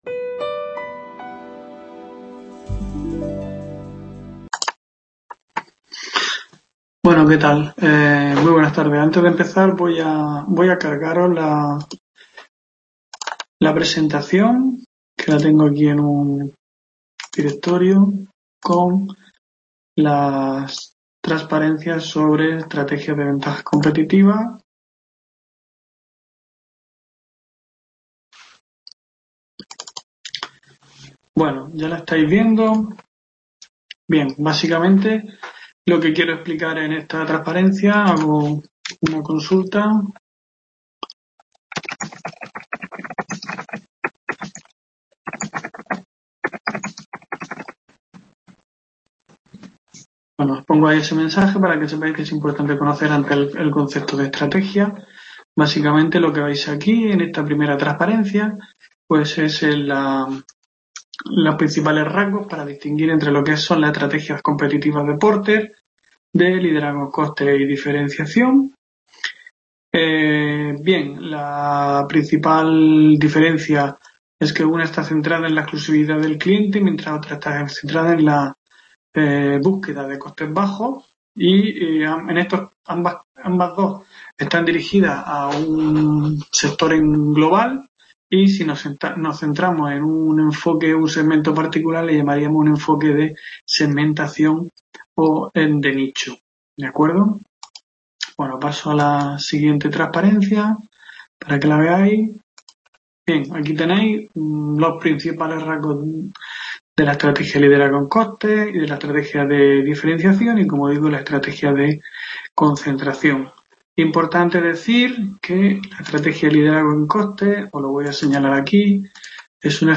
Clase Curso AVIP Fundamentos